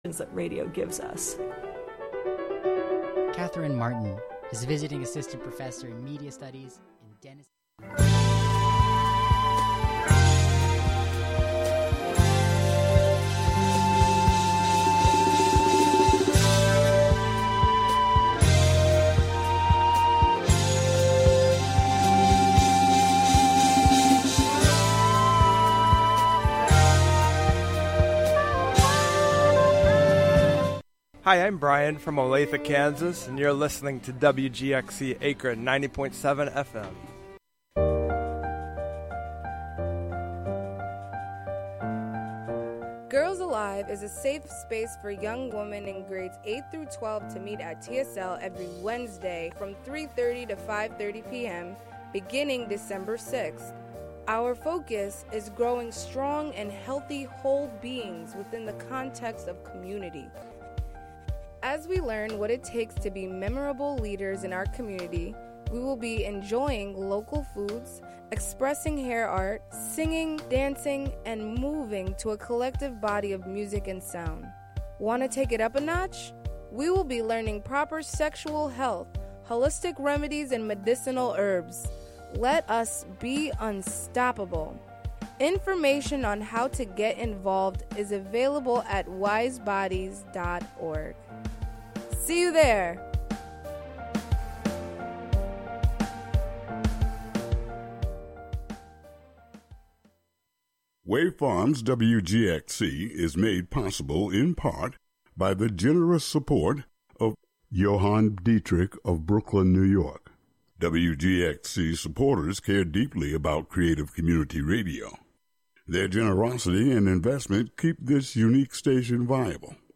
The show begins each day with local headlines, weather, and previews of community events.